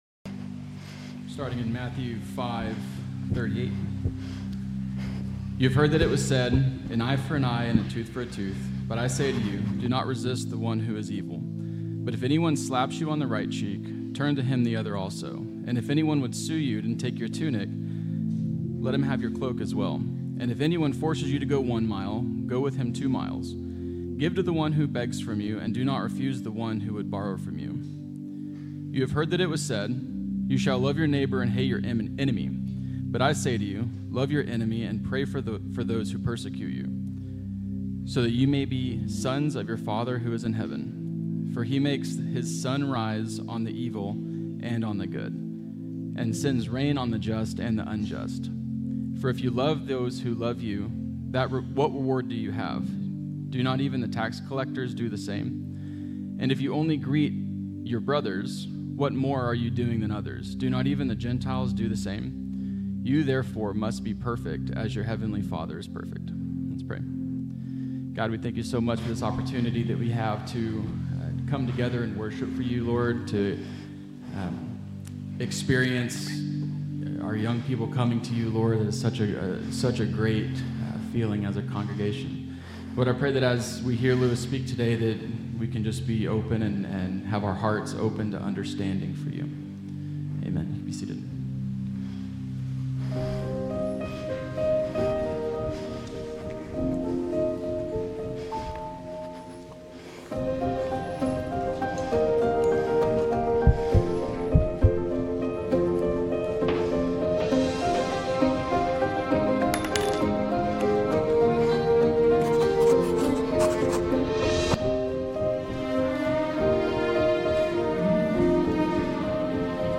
Grace Community Church Lindale Campus Sermons 3_2 Lindale Campus Mar 03 2025 | 00:29:32 1x 00:00 / 00:29:32 Subscribe Share RSS Feed Share Link Embed